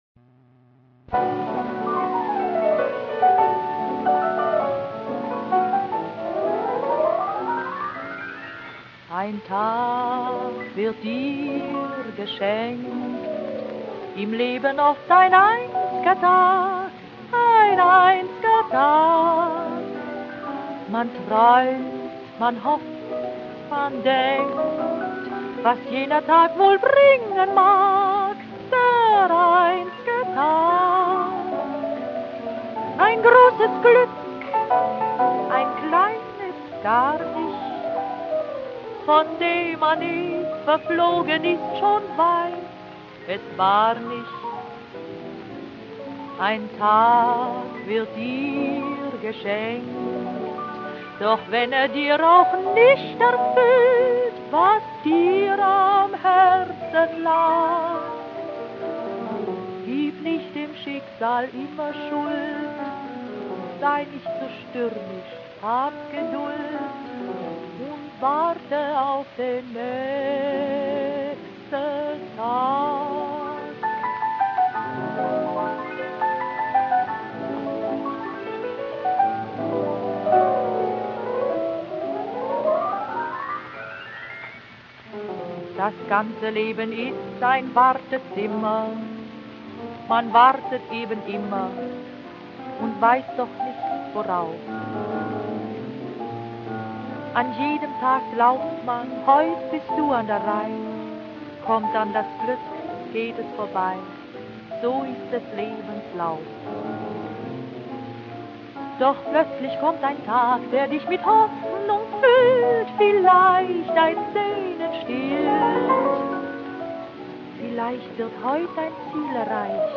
Chanson
an 2 Flügeln